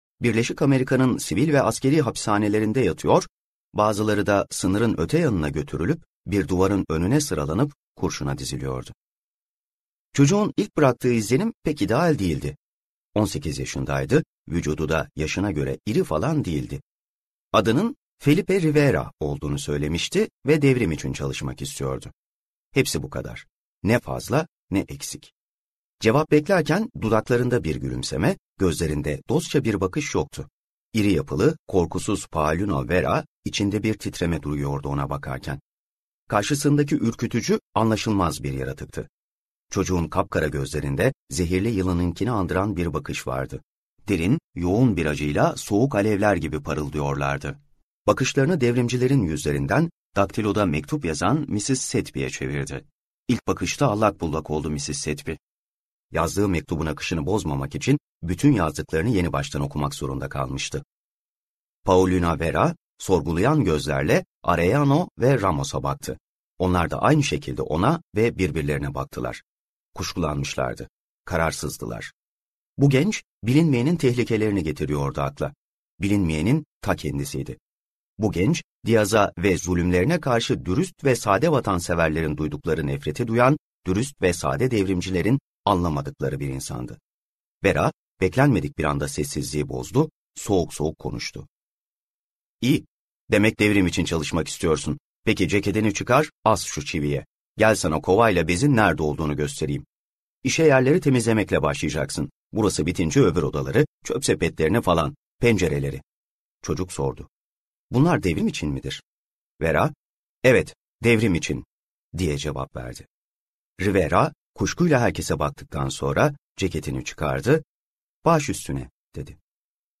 Meksikalı - Seslenen Kitap